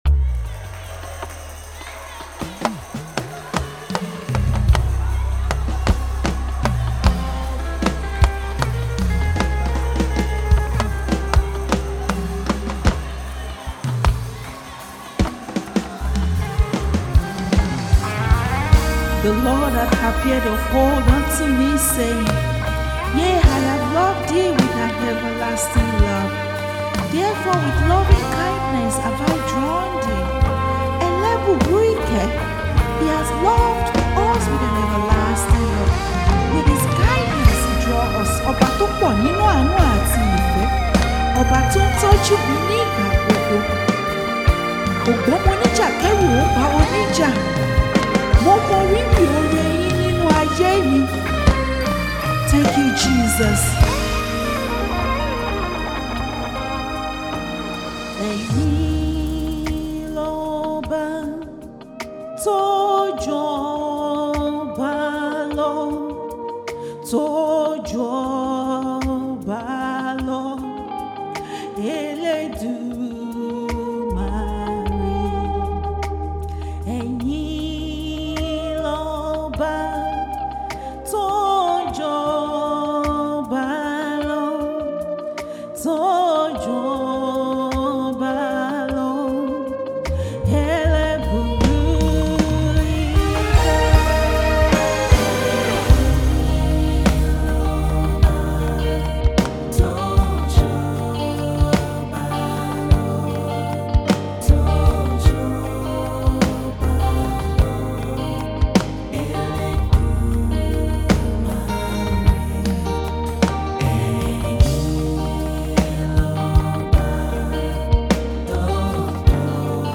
Gospel Music 🎶